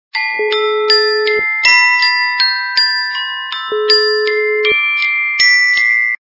» Звуки » звуки для СМС » Звук - Музыкальная шкатулка